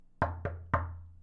SFX敲门(Knocking)音效下载